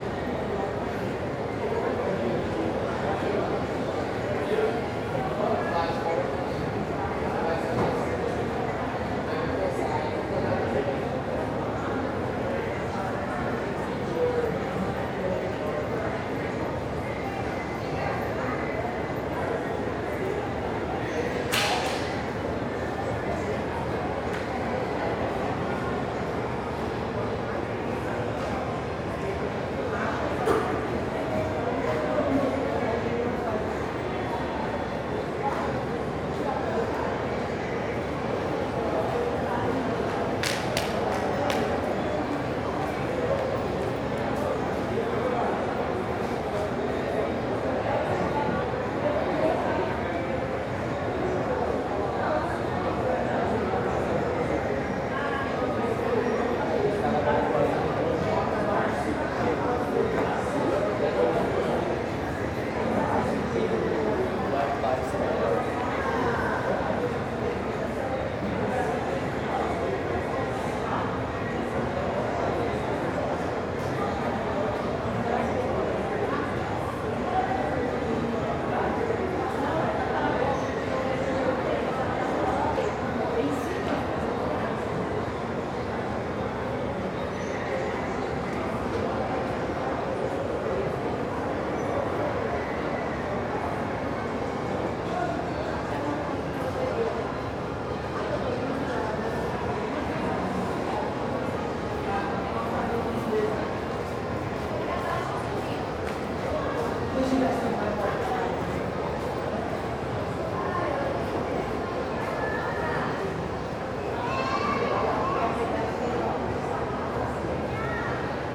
CSC-04-250-LE - Ambiencia araguaia shopping corredor pessoas e passos.wav